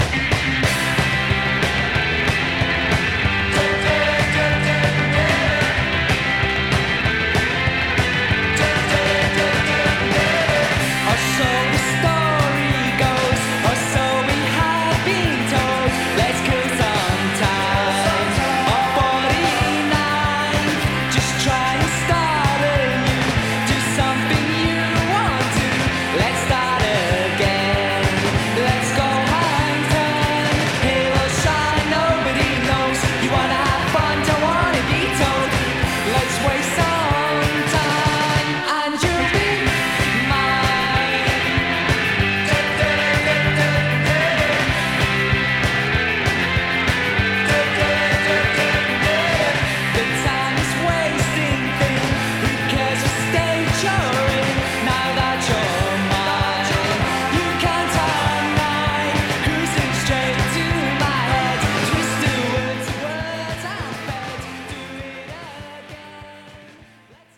サビで大盛り上がりキャッチーなギター・ポップ系クラブヒット